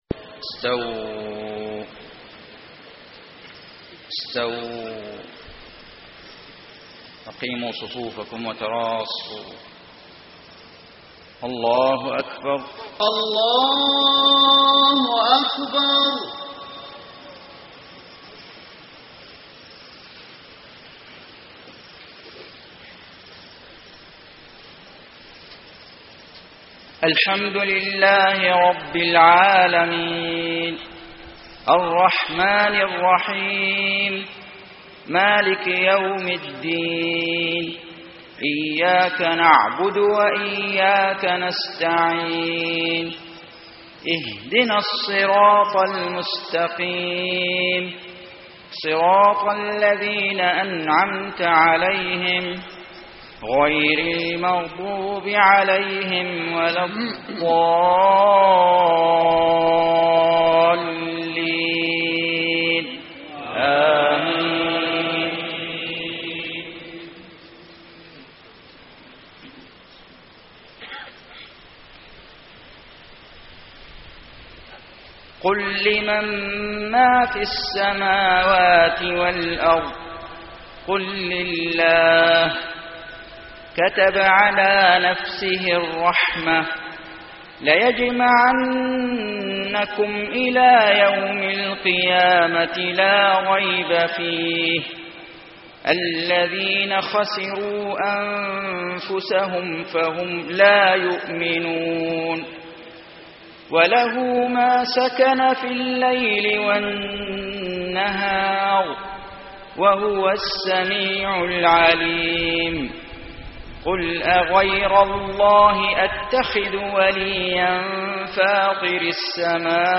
صلاة المغرب 5-6-1434 من سورة الأنعام > 1434 🕋 > الفروض - تلاوات الحرمين